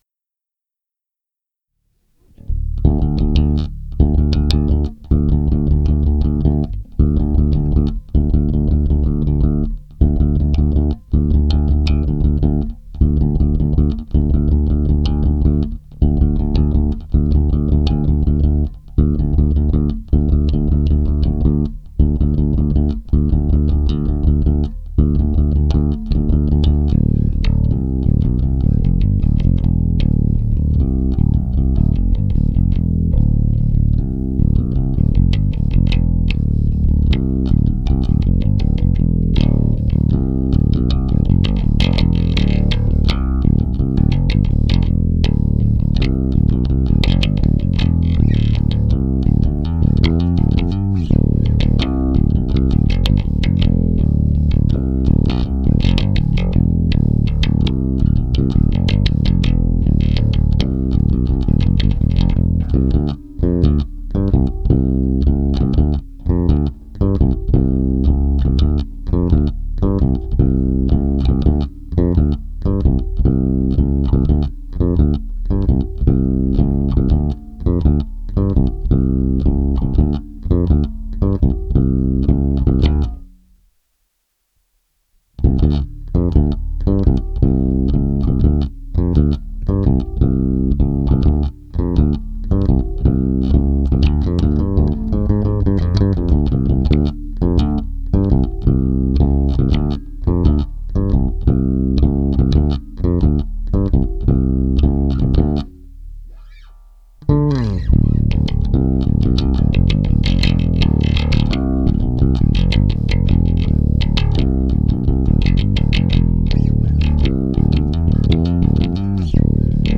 basse uniquement